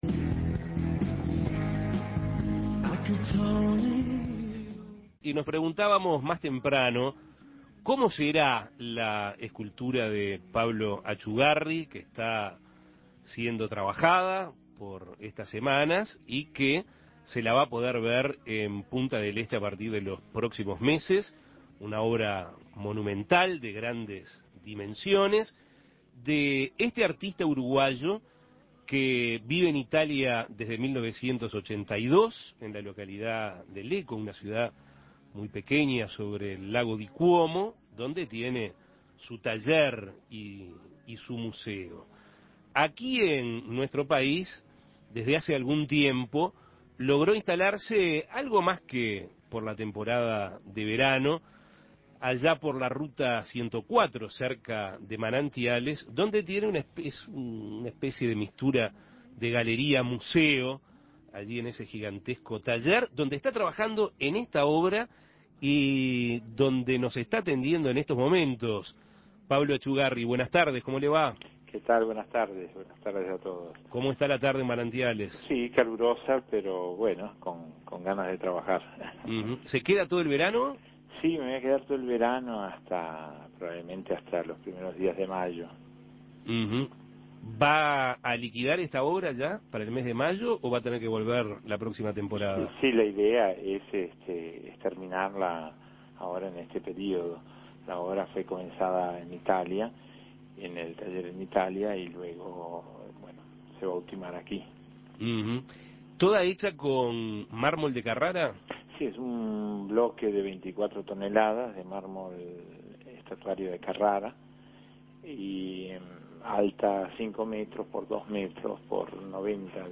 Entrevistas Monumental obra de Pablo Atchugarry en Punta del Este Imprimir A- A A+ El artista uruguayo Pablo Atchugarry vive en Italia desde el año 1982.